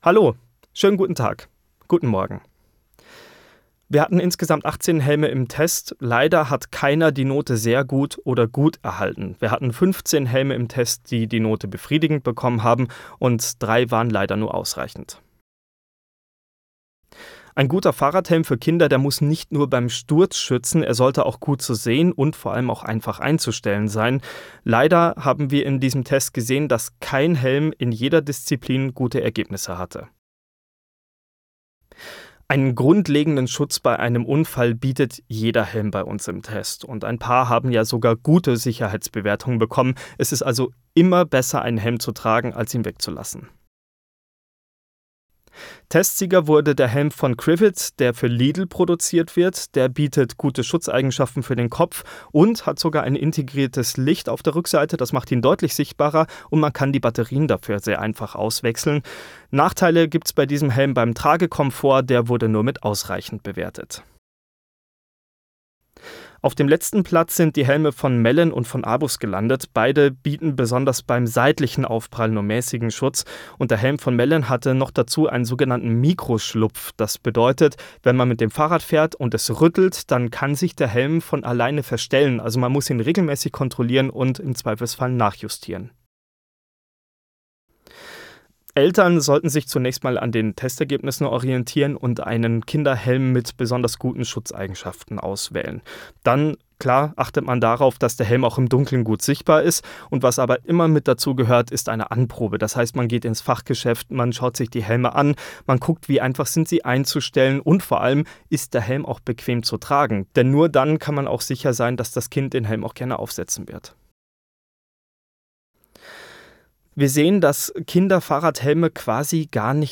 o-toene-adac-kinder-fahrradhelm-test.mp3